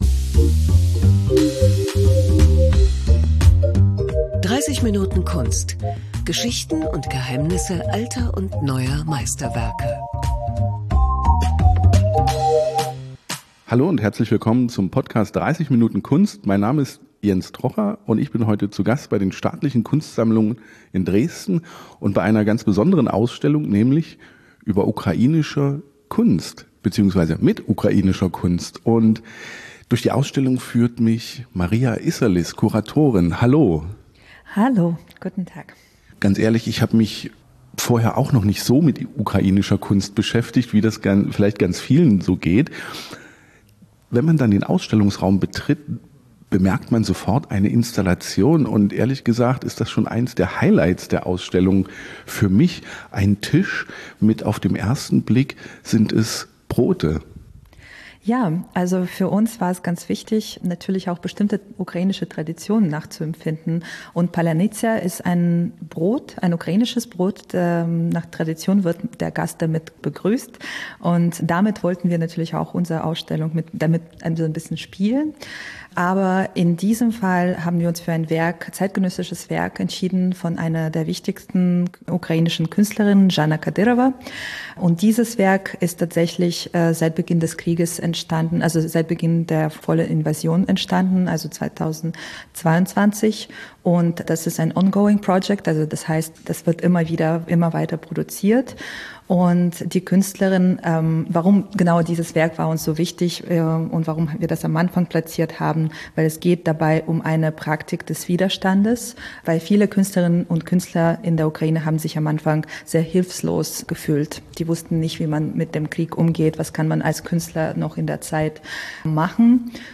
bei einem Rundgang